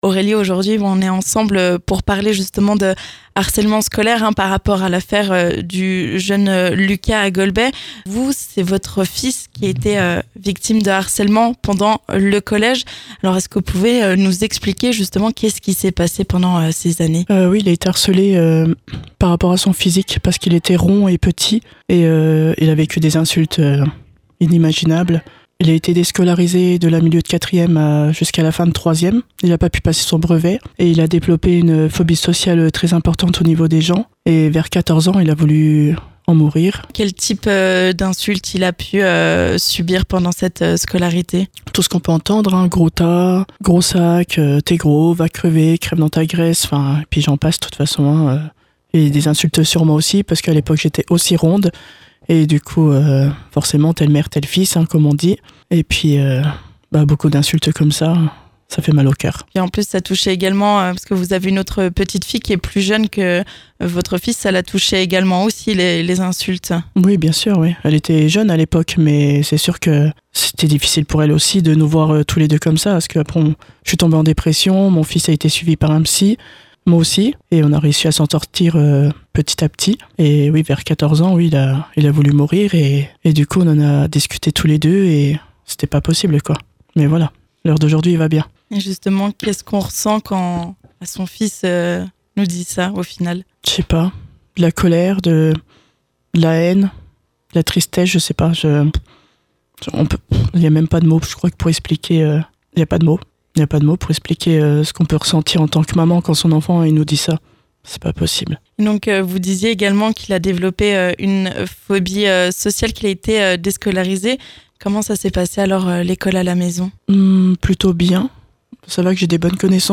Témoignage : comment une maman a aidé son enfant à surmonter le harcèlement scolaire ?